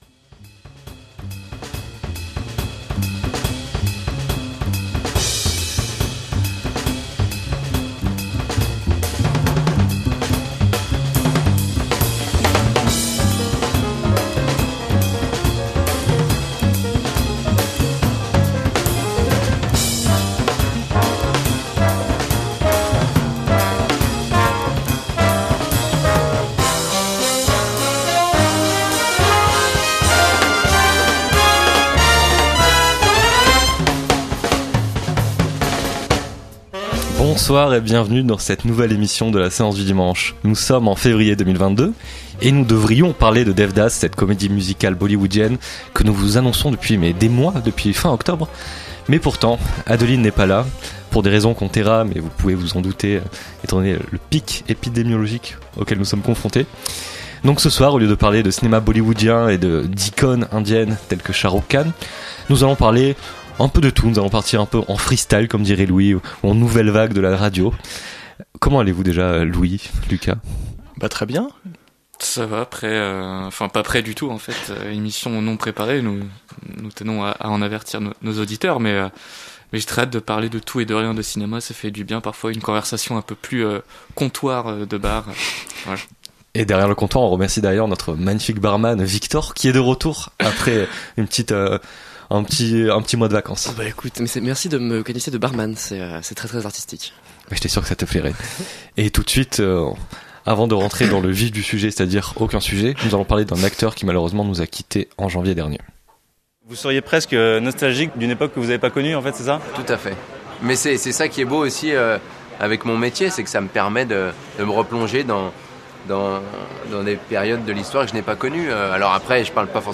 L'émission sur Dev Das étant une nouvelle fois reportée, l'équipe de La Séance du dimanche en profite pour revenir sur un début d'année riche en films et en bonne surprise, que ce soit au cinéma ou sur les plateformes de streaming. Ensuite, un débat porte sur la question de la cinéphilie, et sur leur rapport au cinéma !